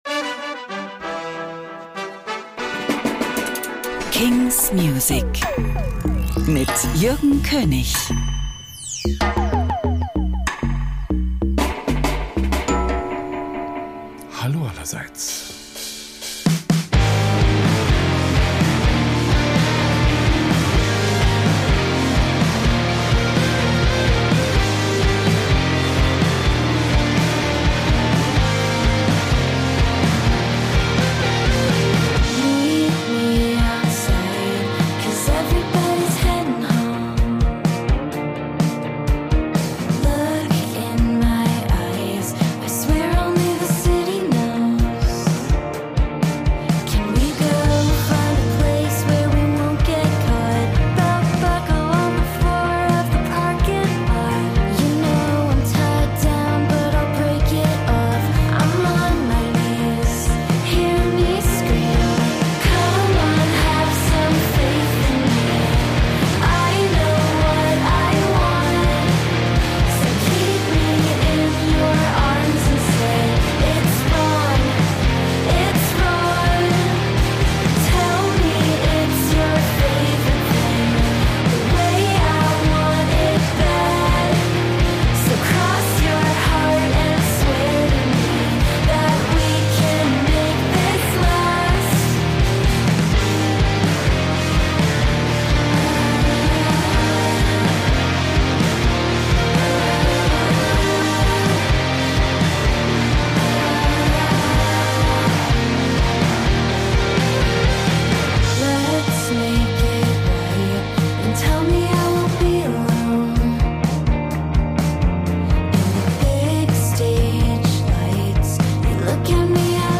great, new indie & alternative releases